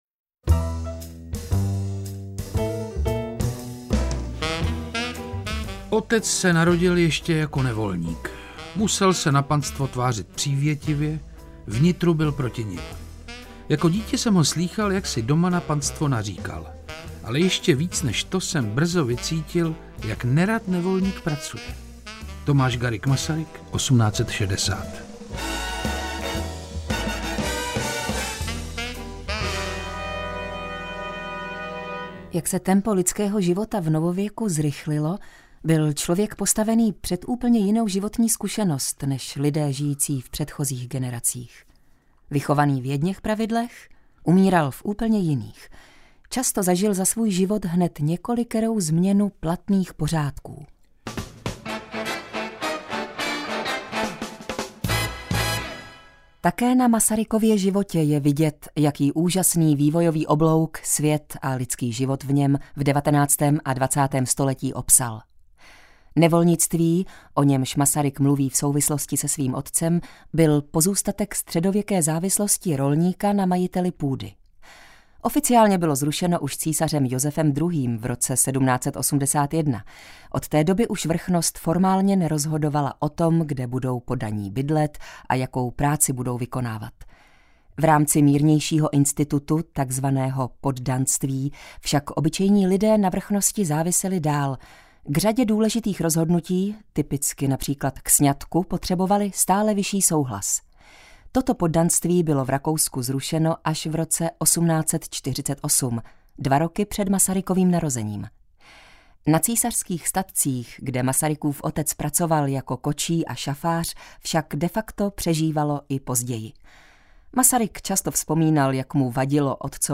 Interpreti:  Dana Černá, Petr Čtvrtníček